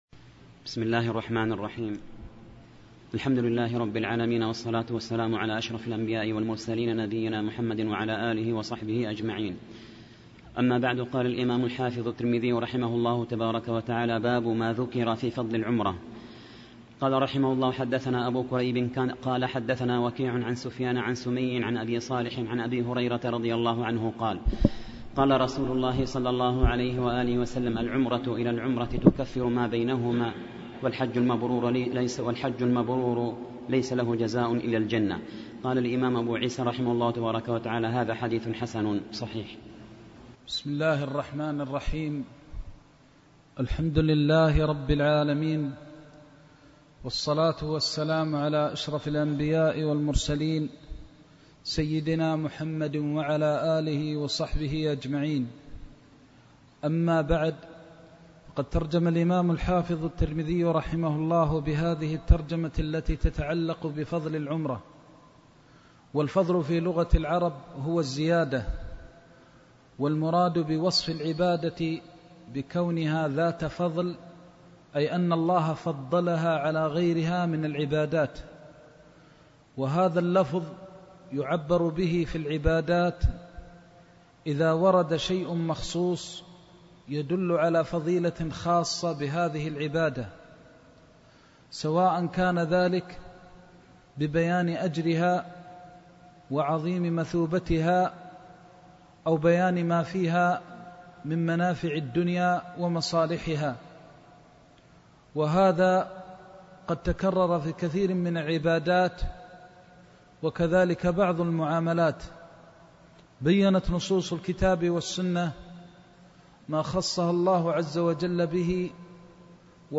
Ansarallah Ø¯Ø±Ø³ Ø³Ù†Ù† Ø§Ù„ØªØ±Ù…Ø°ÙŠ398